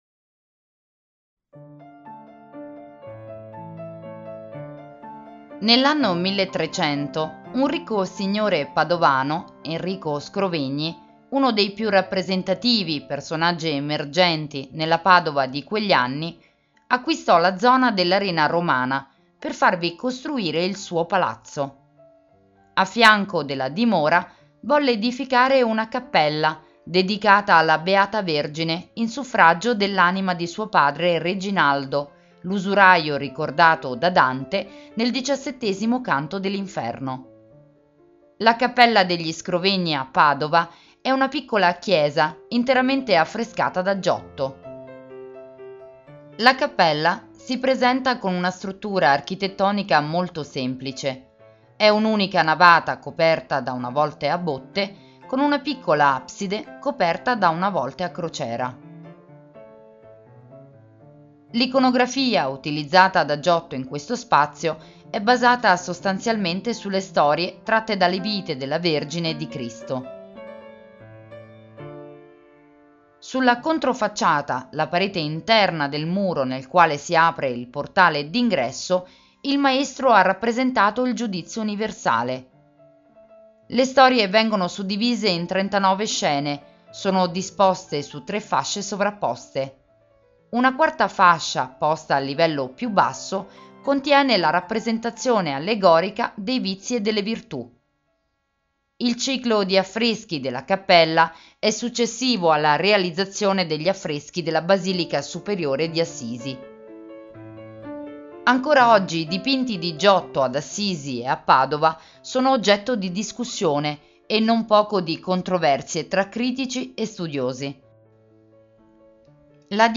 Audioguida Padova - La Cappella degli Scrovegni - Audiocittà